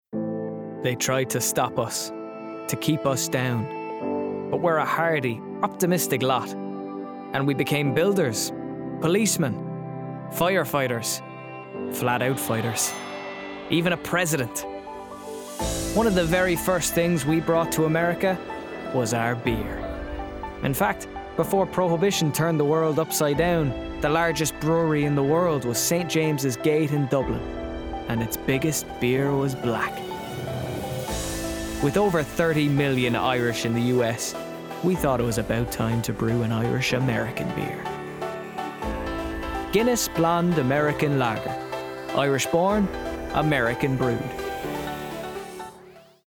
20/30's Irish,
Bright/Energetic/Comedic